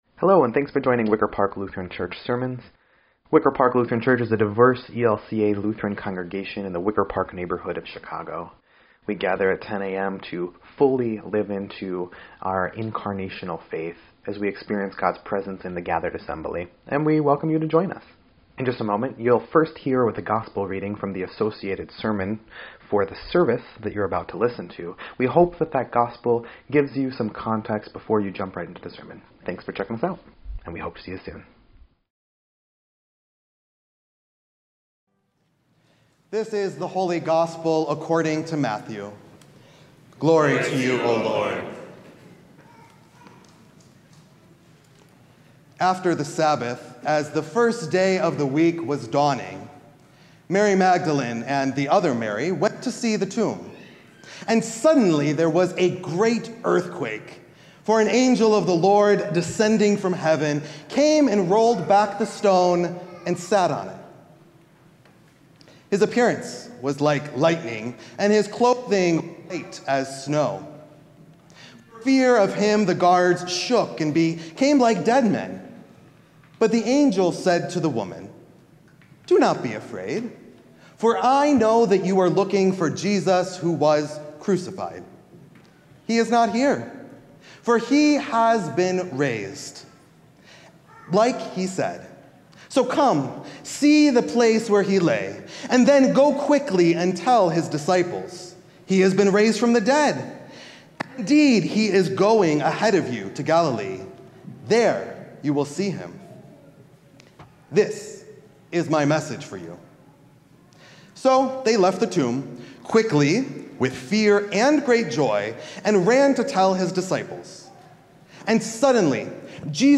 4.5.26-Sermon_EDIT.mp3